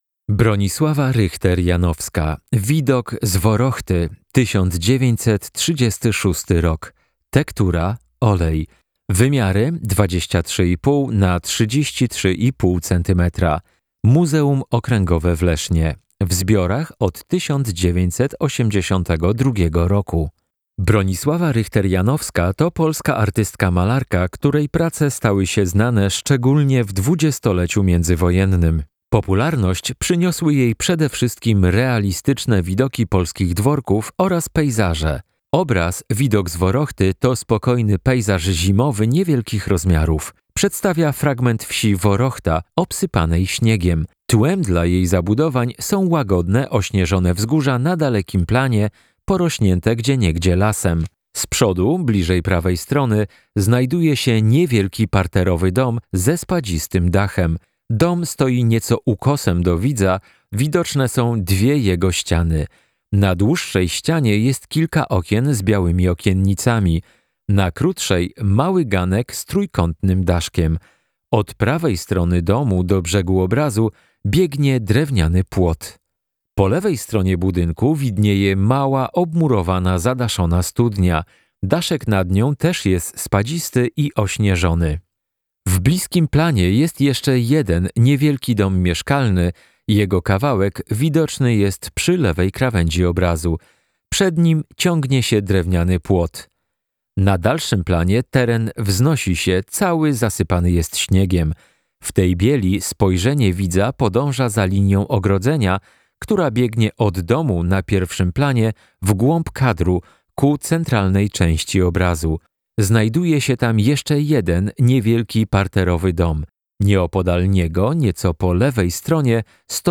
Audiodeskrypcja -